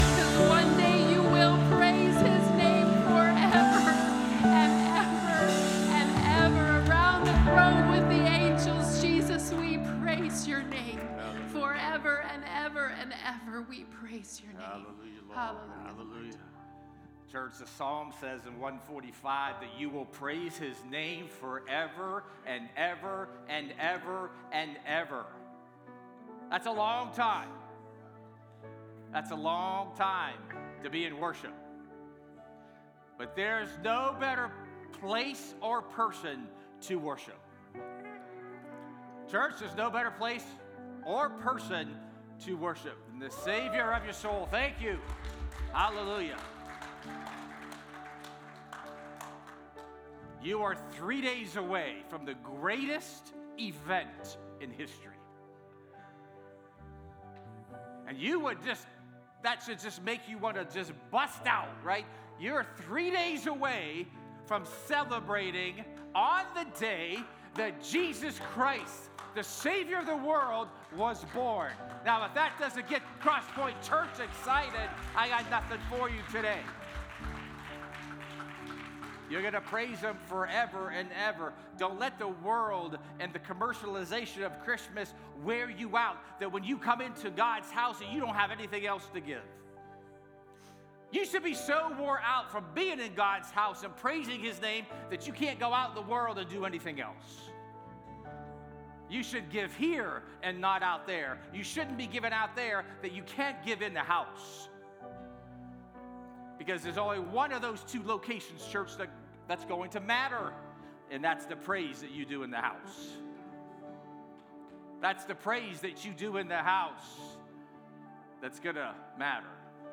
A message from the series "Divine Moments."